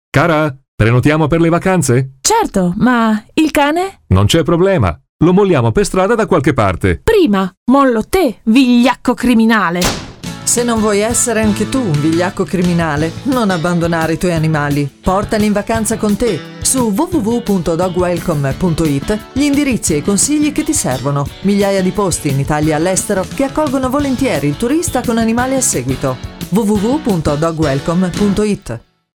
Gli spot sono realizzati da uno studio professionale, quindi del tutto adatti alla messa in onda in qualsiasi genere di radio.